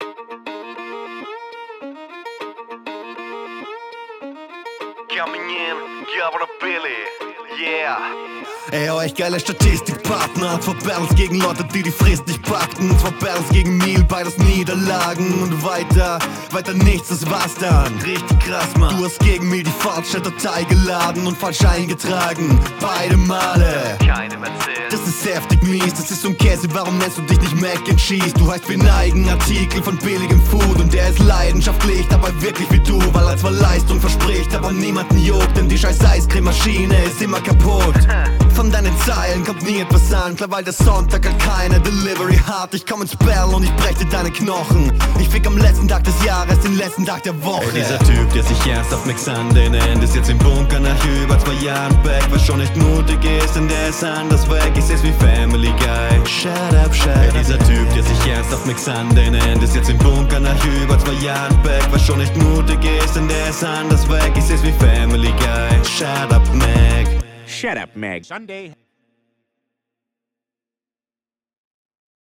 ich steppe zum beat mix/master ist super. frequenztechnisch recht angenehm, kompression kommt dick. was mich …